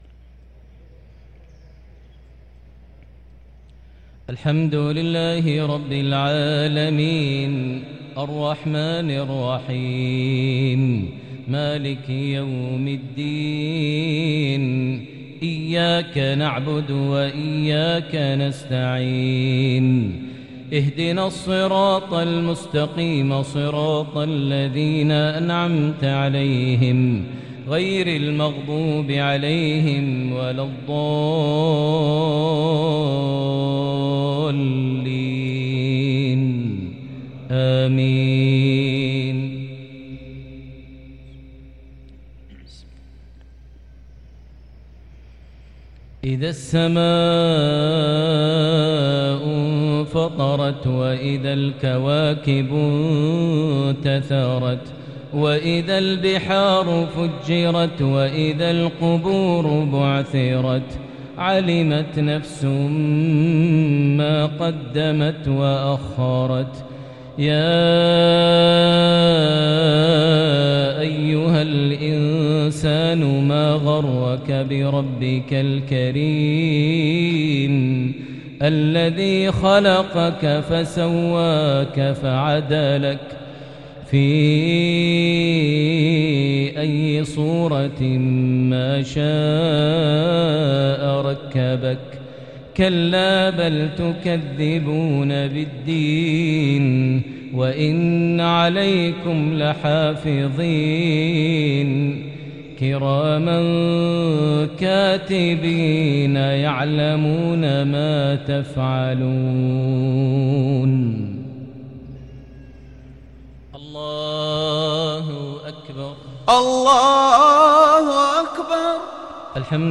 تلاوة جميلة لسورة الانفطار | مغرب الاثنين 9-2-1444هـ > 1444 هـ > الفروض - تلاوات ماهر المعيقلي